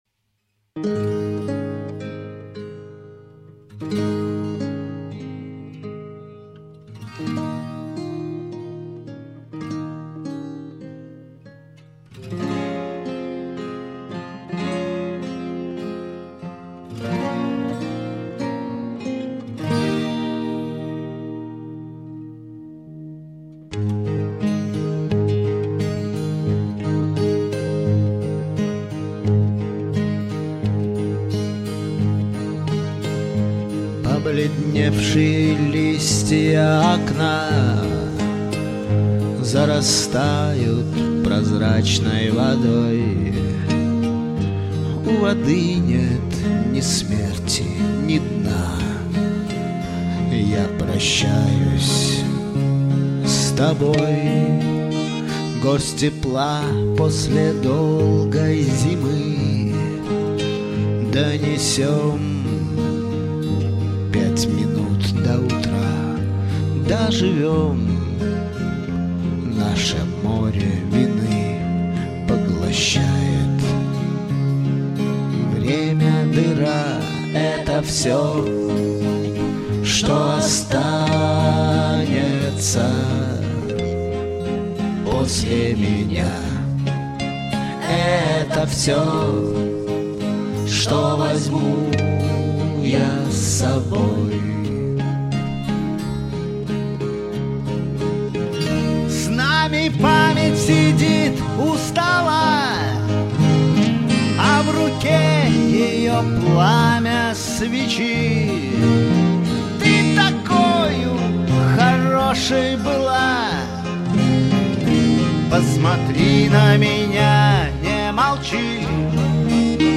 Вокал очень хороший,всё на своих местах.